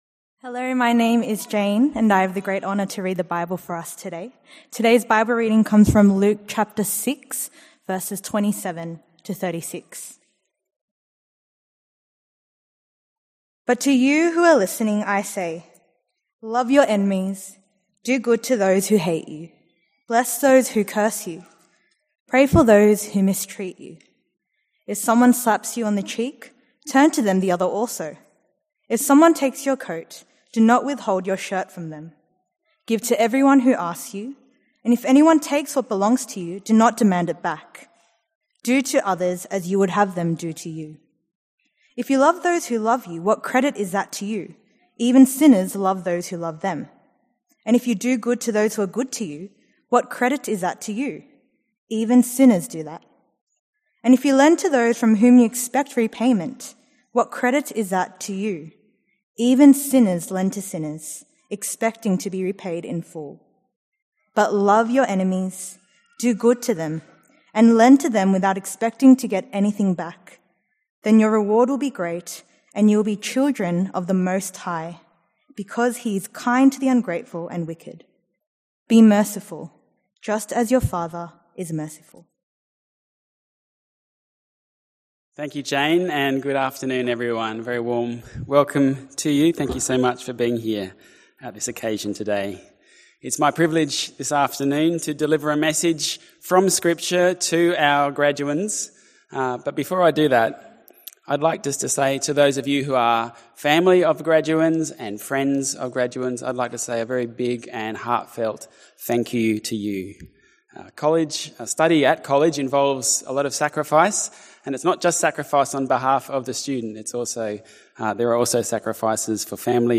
2022_Graduation_Address.mp3